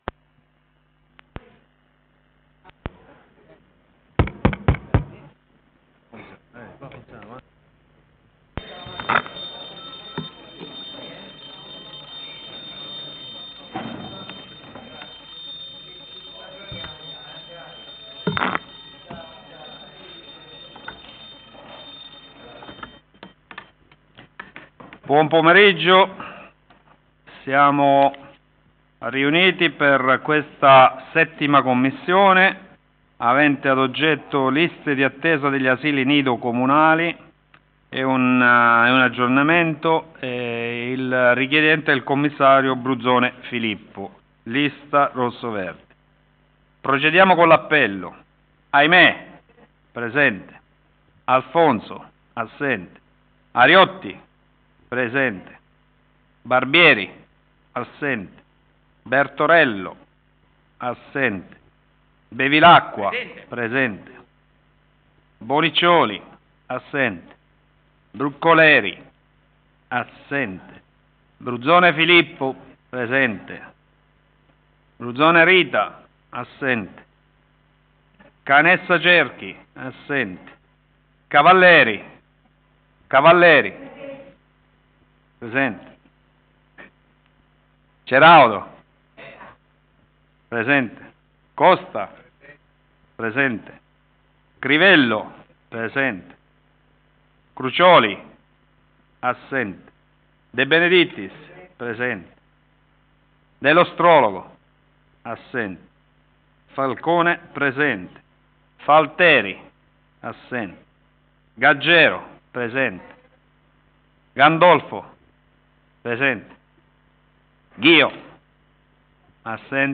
Luogo: presso la sala consiliare di Palazzo Tursi - Albini
Audio seduta